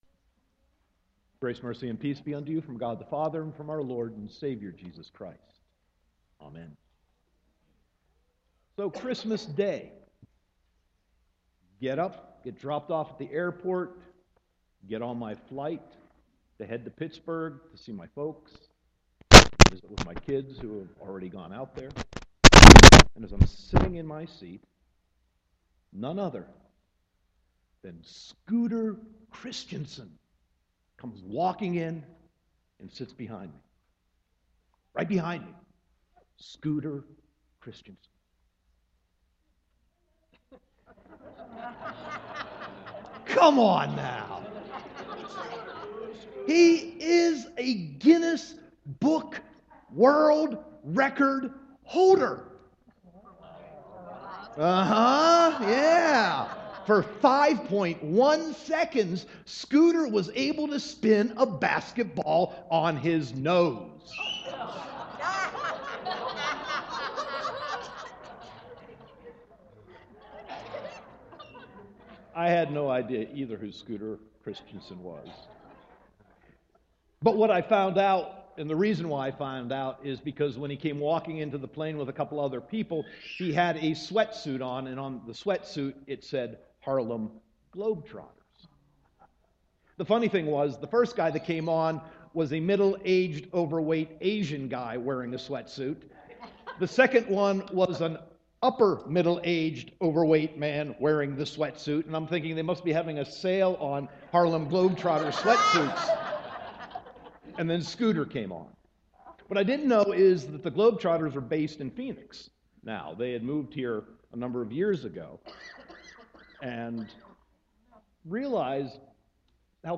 Sermon 2.15.2015